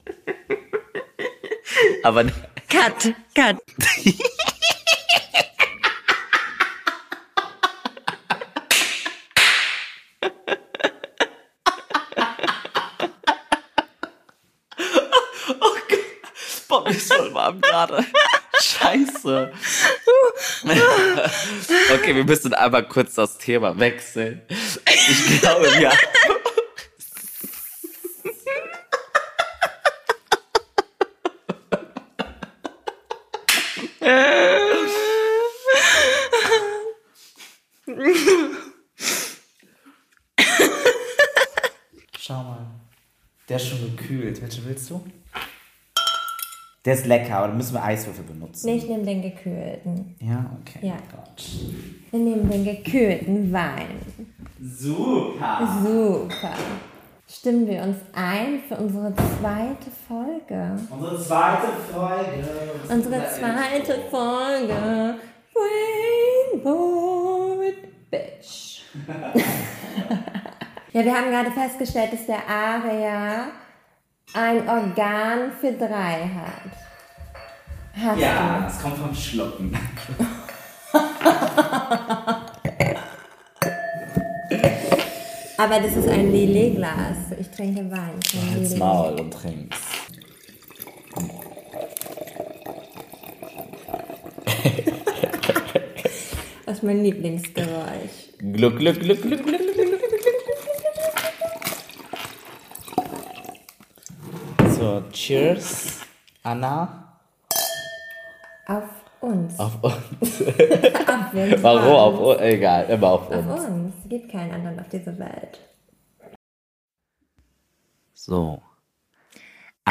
Der Trash Faktor ist hoch: Der Bachelor und seine Rosenanwärterinnen werden inspiziert und es wird eine Menge gelacht.